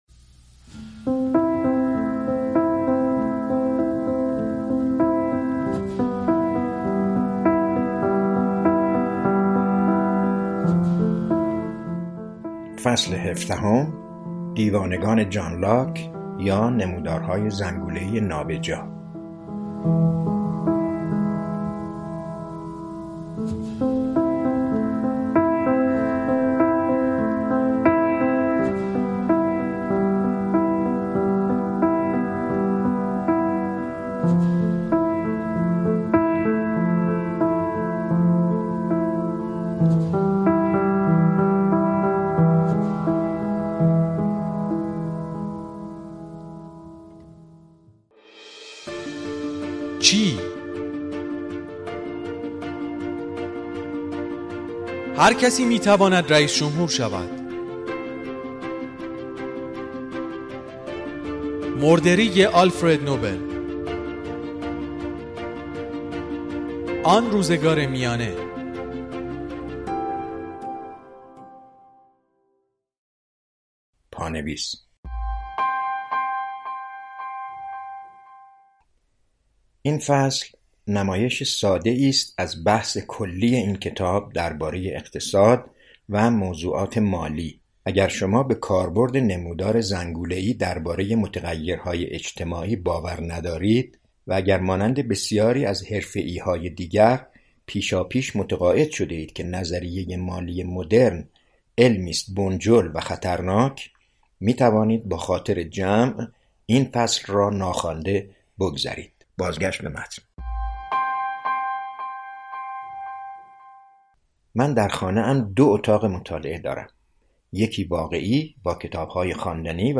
کتاب صوتی قوی سیاه (17)